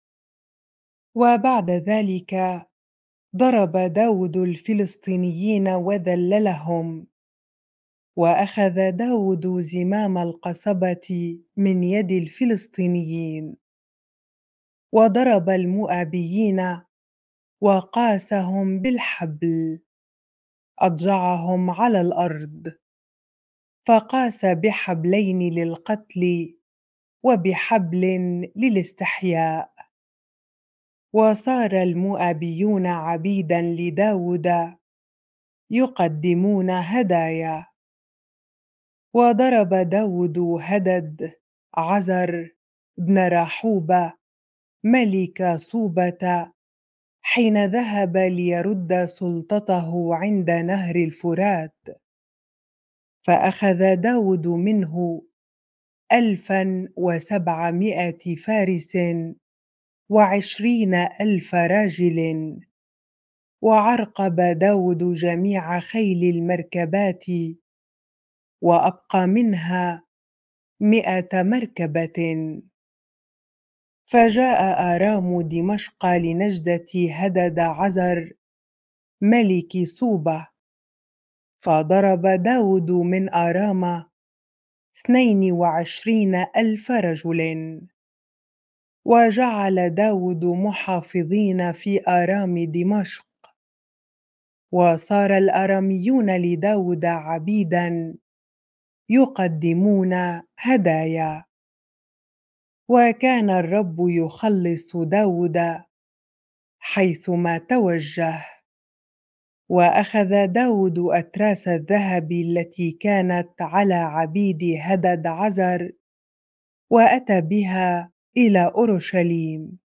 bible-reading-2Samuel 8 ar